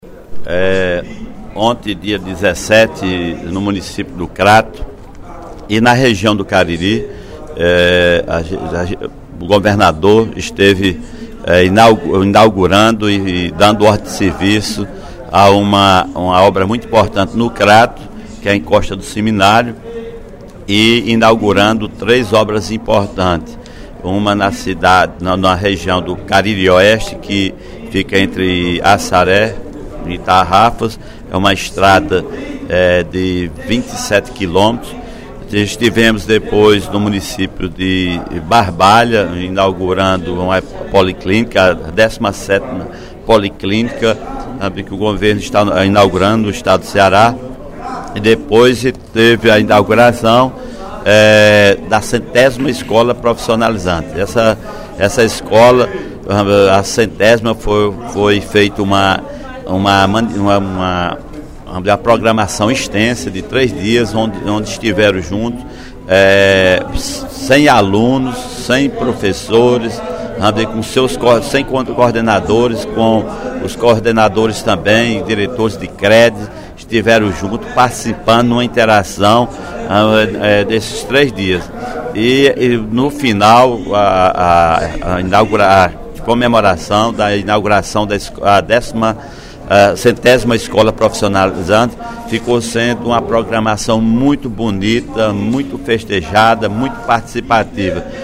Em pronunciamento durante o primeiro expediente da sessão plenária desta quarta-feira (18/12), o deputado Sineval Roque (Pros) destacou a inauguração de obras do Governo do Estado. Entre elas, citou a pavimentação asfáltica de 27 quilômetros da CE-375, no trecho Tarrafas-Assaré.
Em apartes, os deputados Osmar Baquit (PSD), Nenen Coelho (PSD) e Mirian Sobreira (Pros) também ressaltaram os avanços que o “Ceará está conquistando neste Governo”.